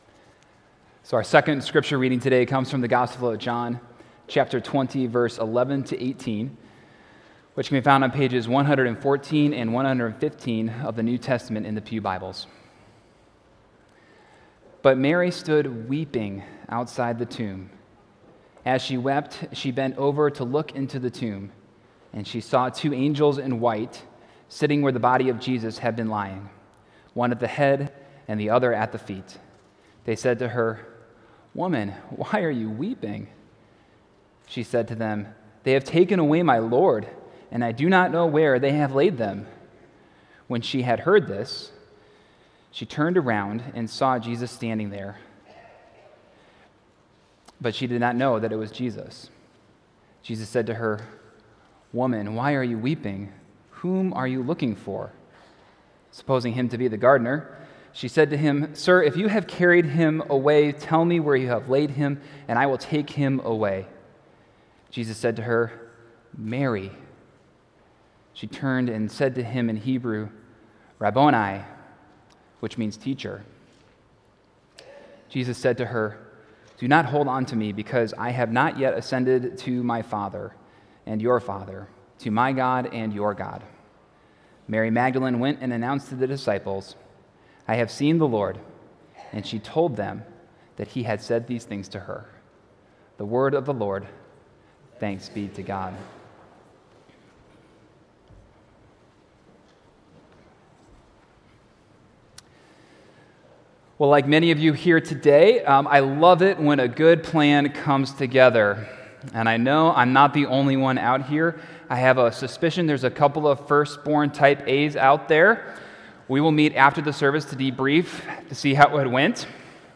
April 21, 2019 – Easter Sunday 2019 – Park Ridge Presbyterian Church